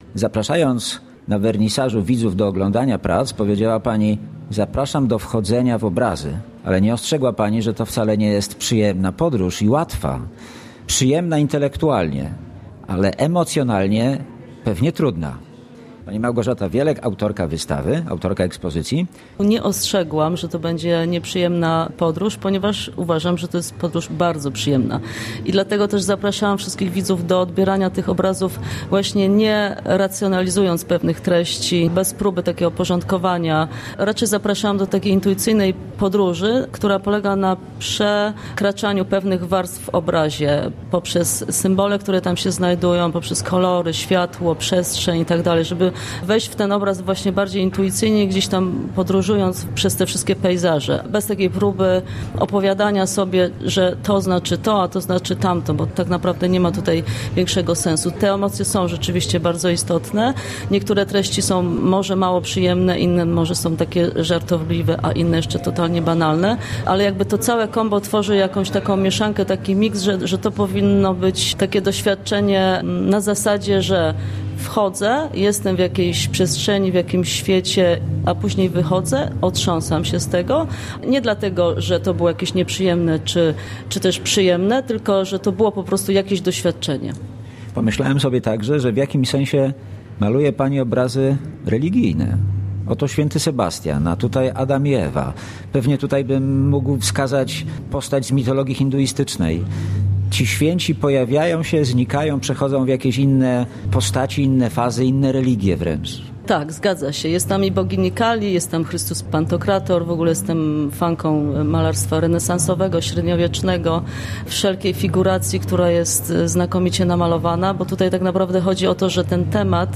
Relacja z wernisażu